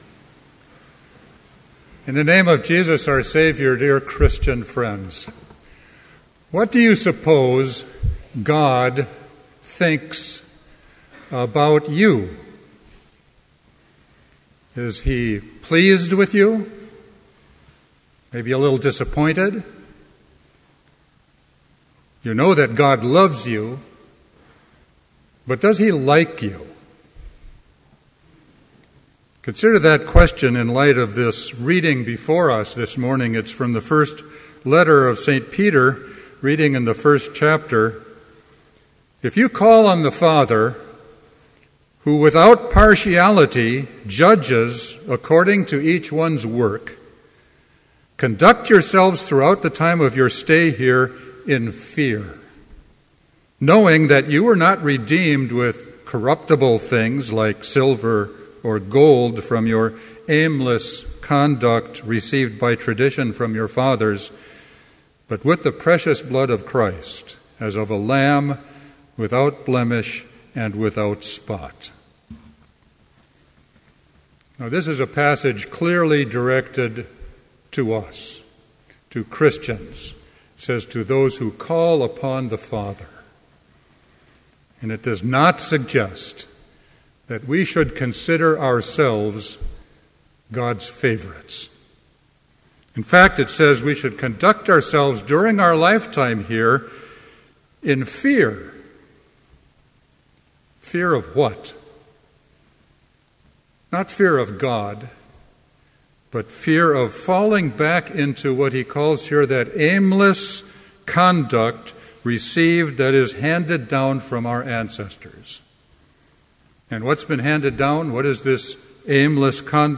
Complete service audio for Chapel - September 13, 2012